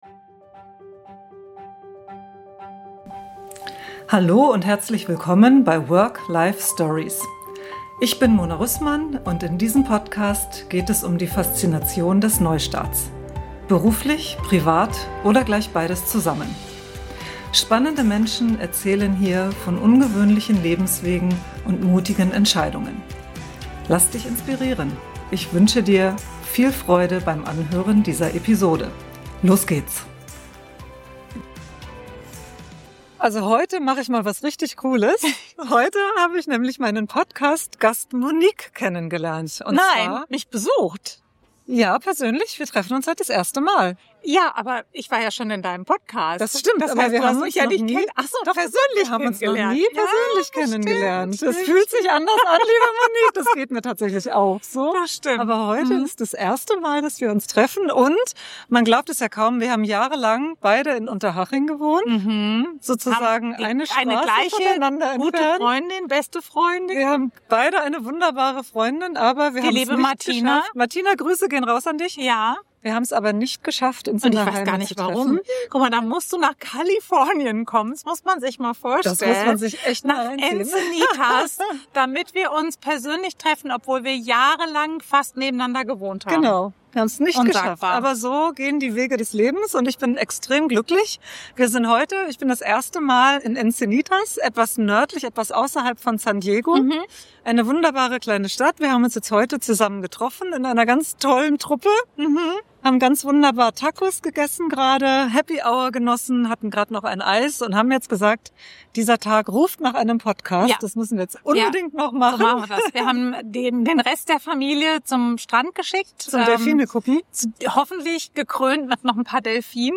**Videoepisode** aus Encinitas, San Diego, Kalifornien, USA.
Roadtrip, Happy Hour und ein Podcast an der Straßenkreuzung.